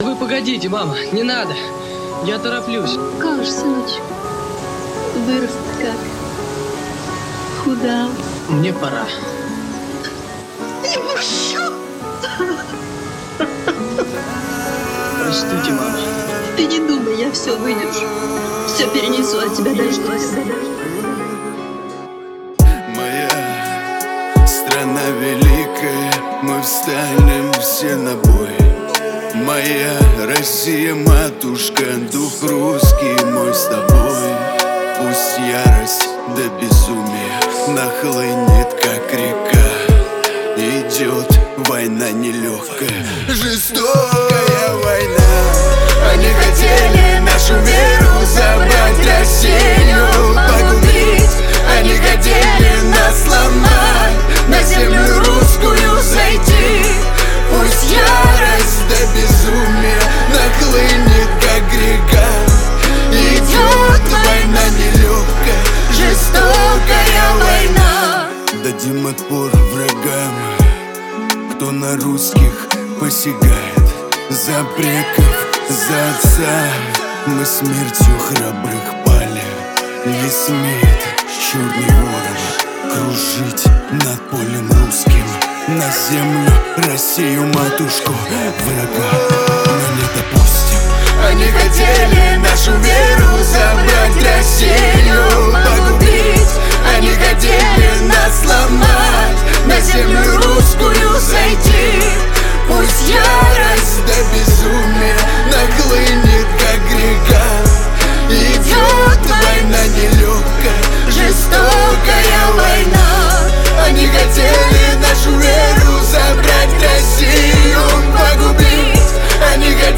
Русская музыка